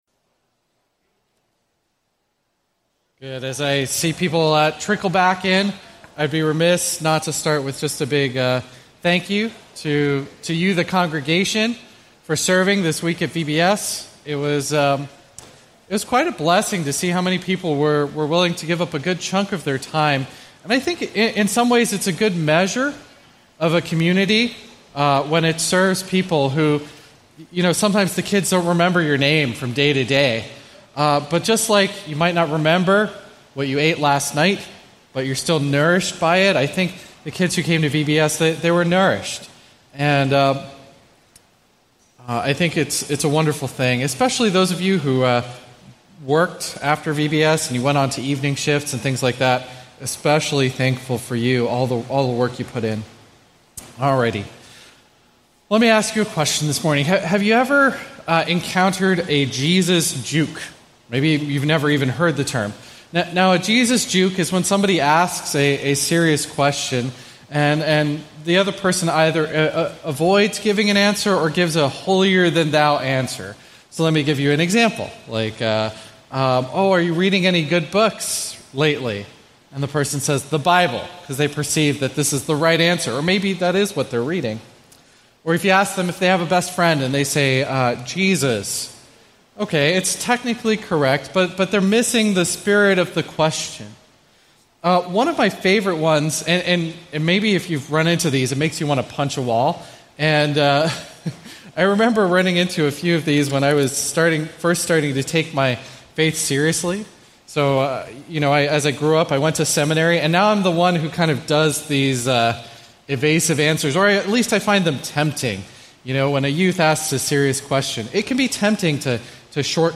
A message from the series "Ecclesiastes."